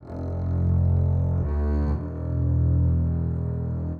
Gear Up (Bass) 120BPM.wav